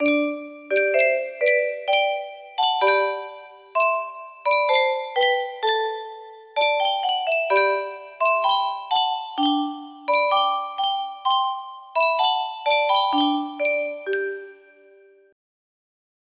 Yunsheng Music Box - Unknown Tune 2375 music box melody
Full range 60